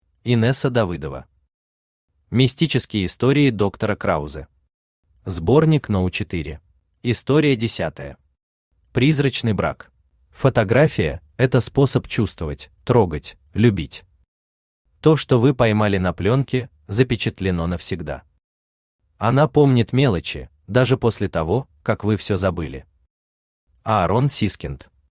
Аудиокнига Мистические истории доктора Краузе. Сборник №4 | Библиотека аудиокниг
Сборник №4 Автор Инесса Давыдова Читает аудиокнигу Авточтец ЛитРес.